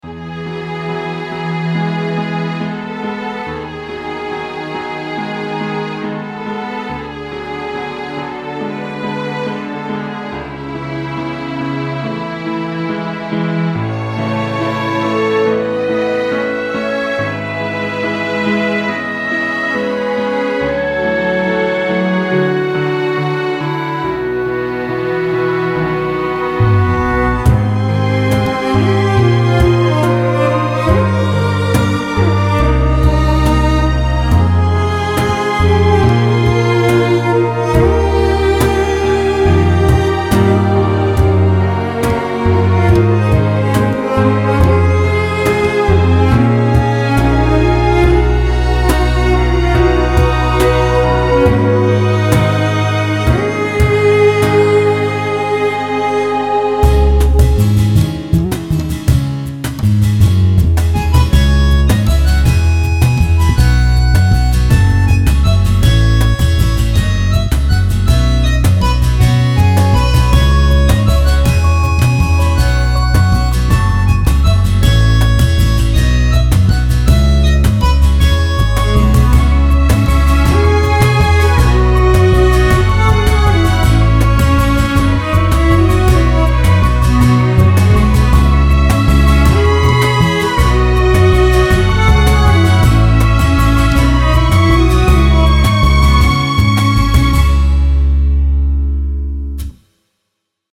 旋律庄重而不失优雅，皇家风范体现得淋漓尽致呢~